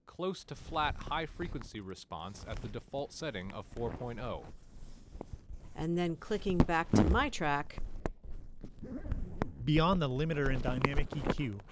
We can then mix in some isolated rustle noise, to create a nosiy spectrogram where the true separated sources are known.
Noisy spectrogram used for network input when we add rustle to the clean speech example.
noisy_speech.wav